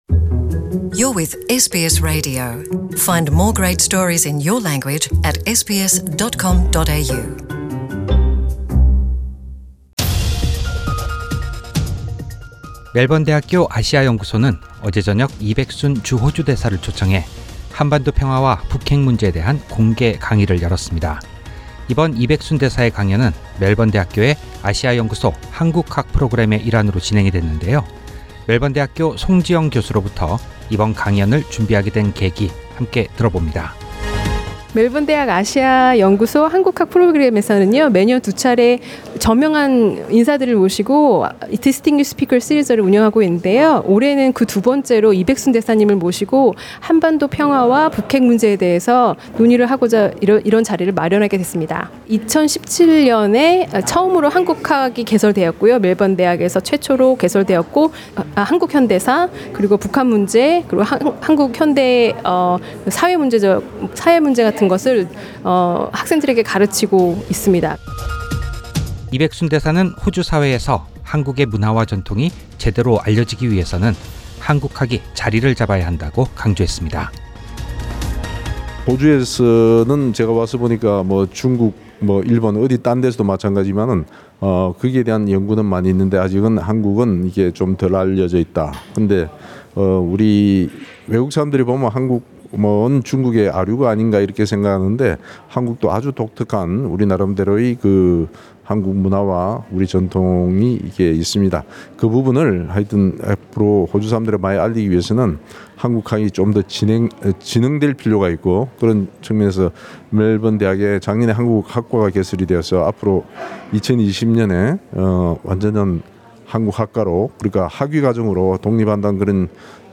Mr Lee Baeksoon, Ambassador of the Republic of Korea to Australia, discussed the ‘Inter-Korean Peace-Building and Denuclearisation of North Korea’ at the seminar in the Melbourne University Asia Institute’s Korean Studies Distinguished Speakers Series.